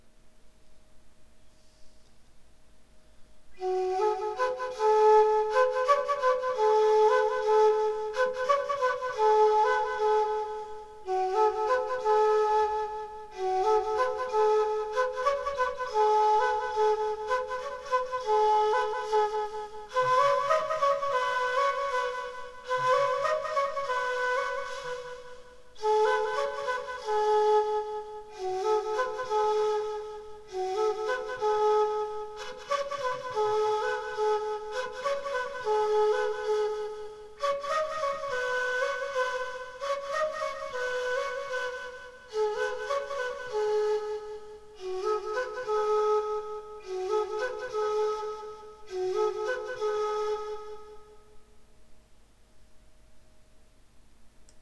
somewhere between soundscape and music…
quena-melody.mp3